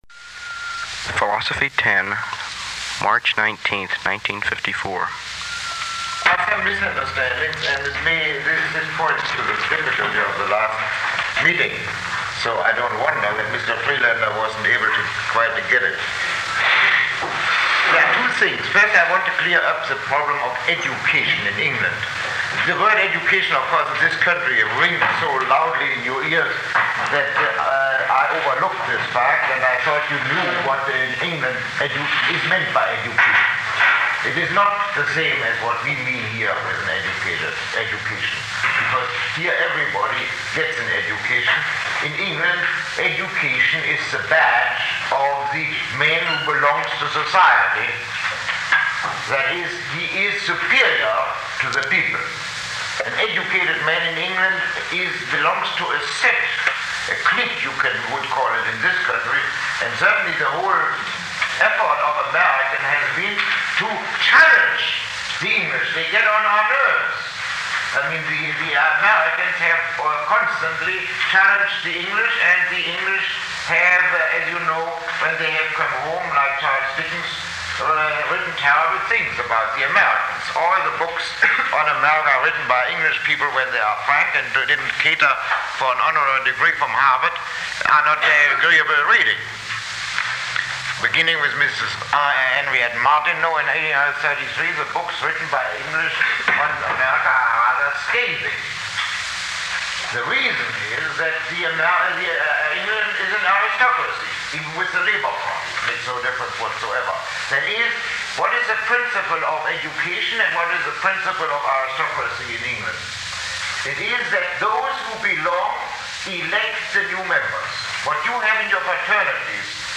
Lecture 12